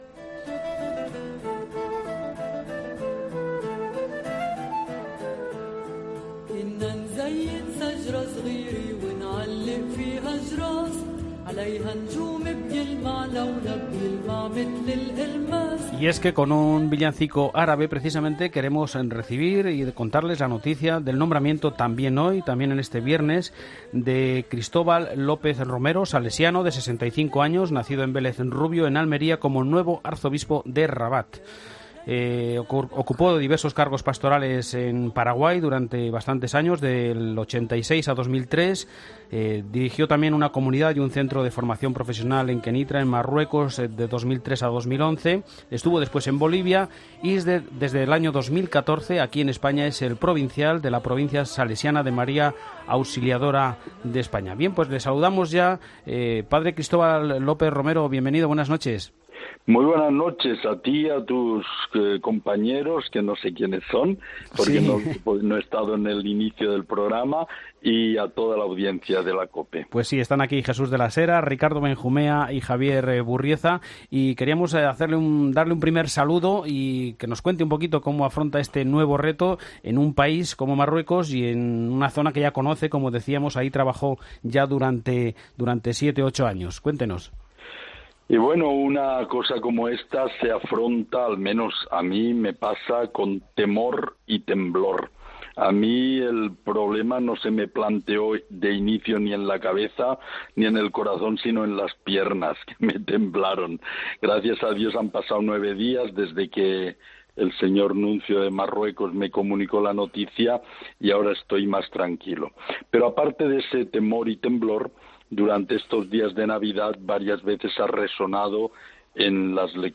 Escucha aquí la entrevista a Cristóbal López Romero en 'La Linterna de la Iglesia'